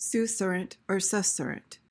PRONUNCIATION:
(soo/suh-SUHR-uhnt)